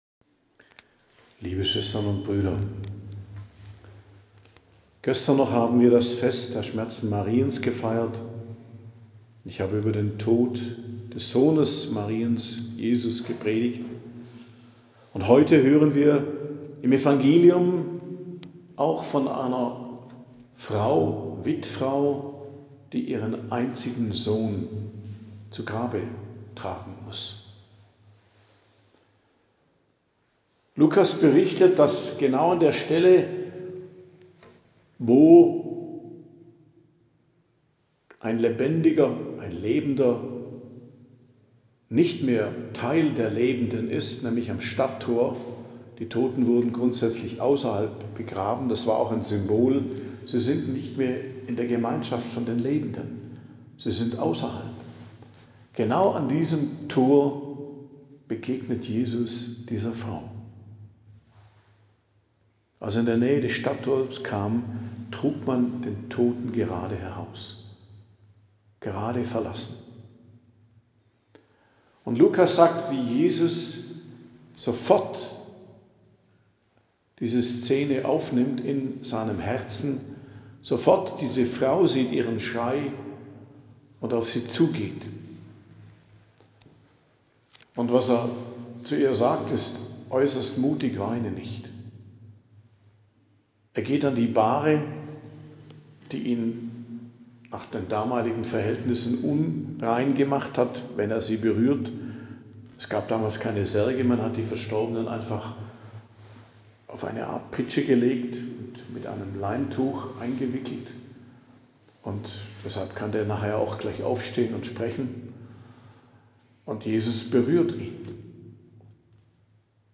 Predigt am Dienstag der 24. Woche i.J., 16.09.2025